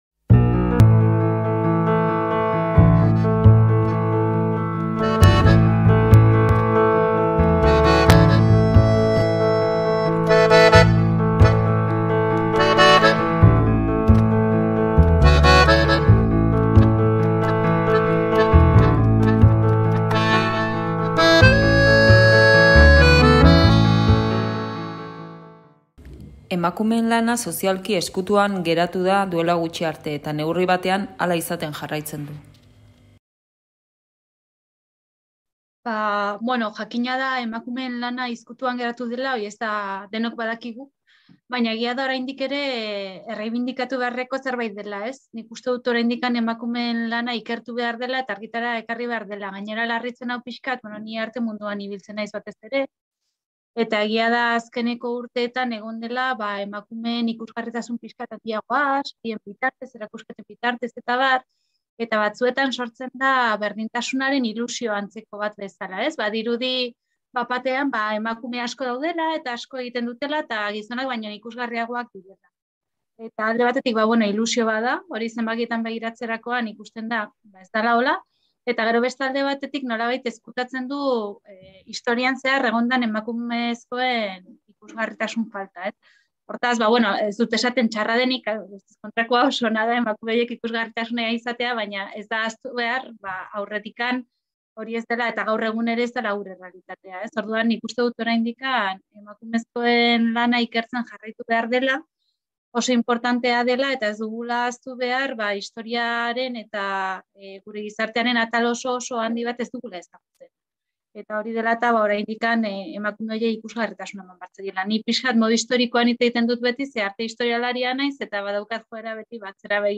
Eusko Ikaskuntzako Solasaldiak